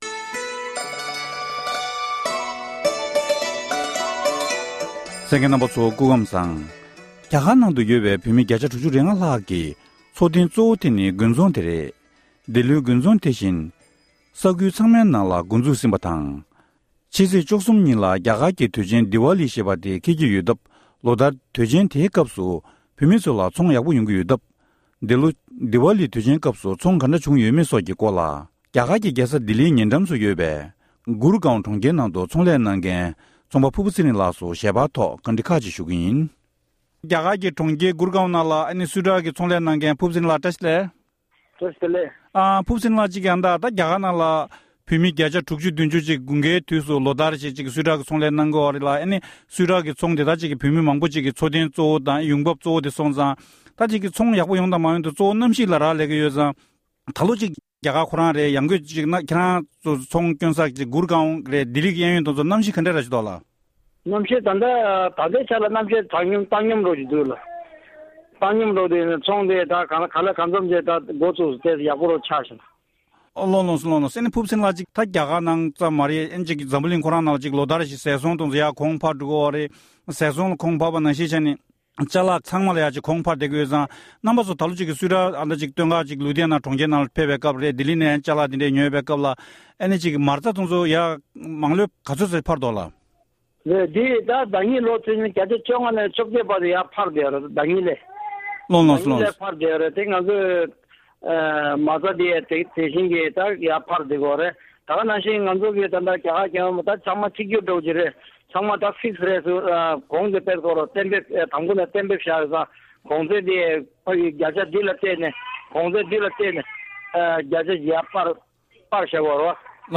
བཀའ་འདྲི་ཞུས་པར་གསན་རོགས༎